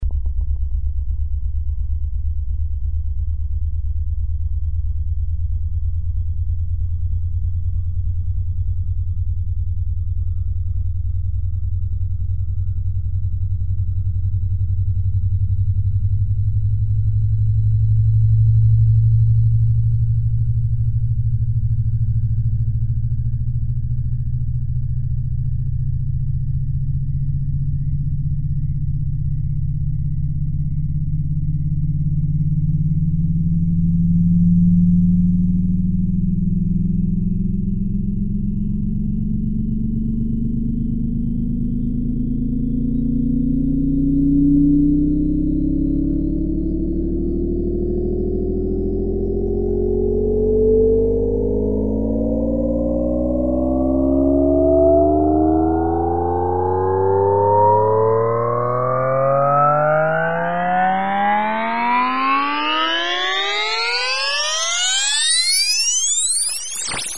Descarga de Sonidos mp3 Gratis: zumbido 2.
descargar sonido mp3 zumbido 2